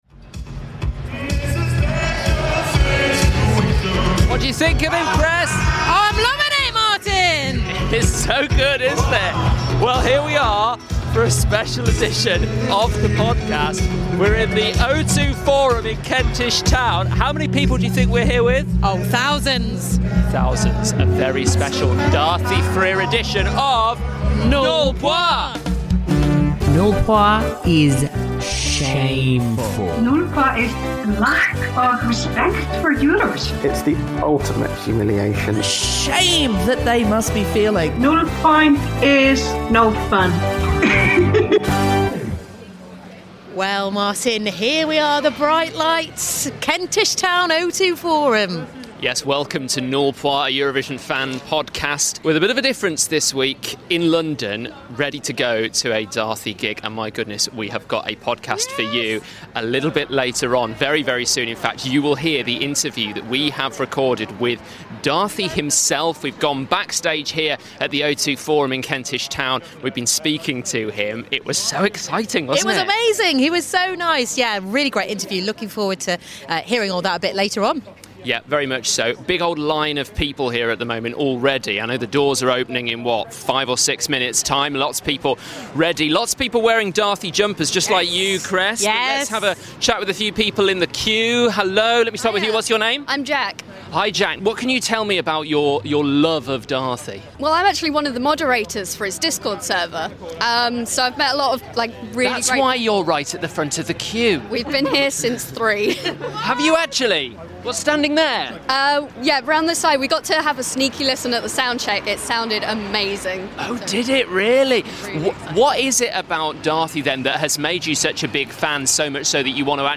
Features a special interview with Daði Freyr! Daði represented Iceland in 2021 and would have been its entry for the cancelled 2020 competition.